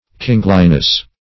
Meaning of kingliness. kingliness synonyms, pronunciation, spelling and more from Free Dictionary.
Kingliness \King"li*ness\, n.